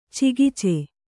♪ cegice